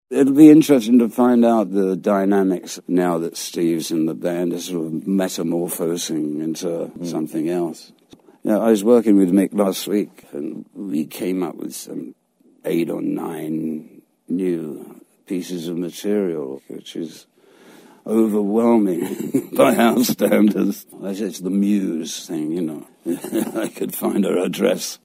Keith Richards on his recent songwriting session with Mick Jagger.  [Courtesy of CBS Sunday Morning]